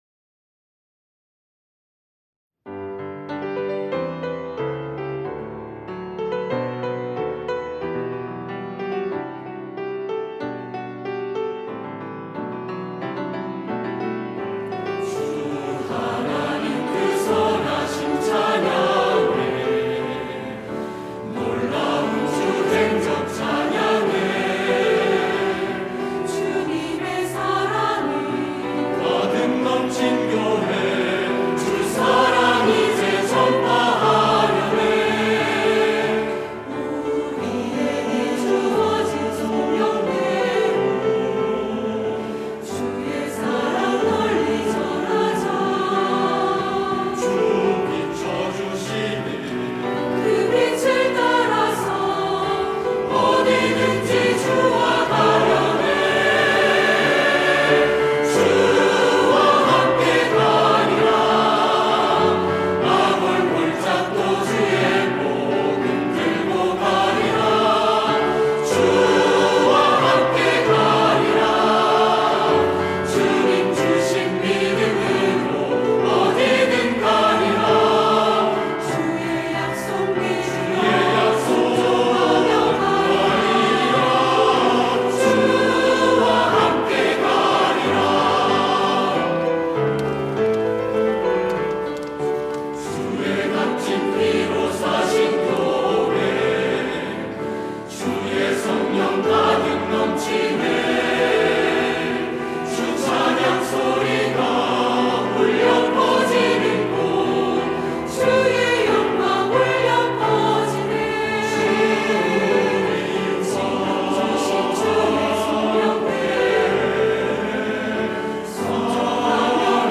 할렐루야(주일2부) - 주와 함께 가리라
찬양대